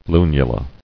[lu·nu·la]